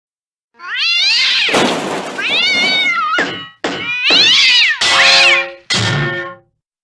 Cartoon Cat Fighting Sound Effect Free Download
Cartoon Cat Fighting